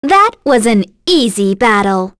Selene-Vox_Victory_b.wav